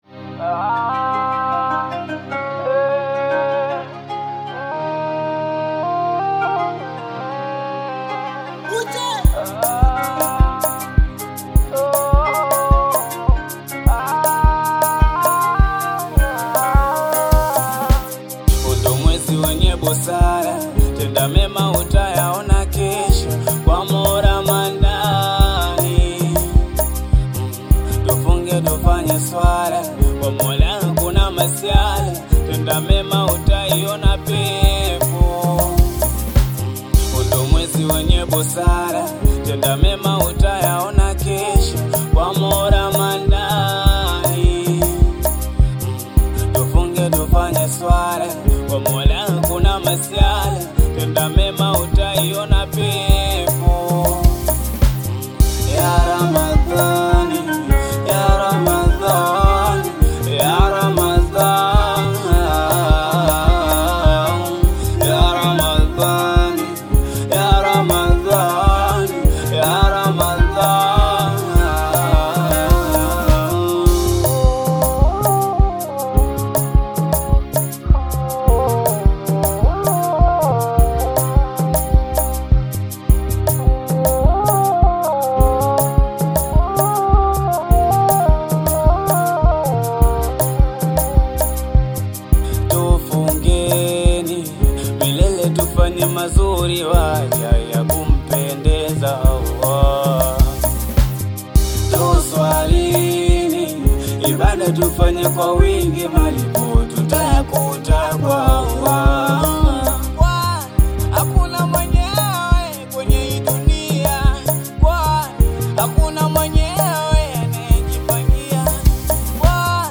spiritually‑inspired Afro‑Bongo single